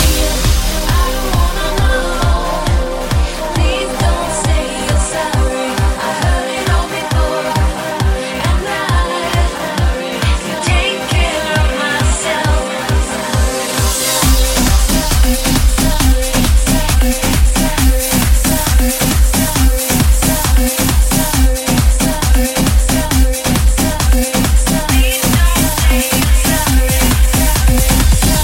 Genere: tribal,anthem,circuit,remix,hit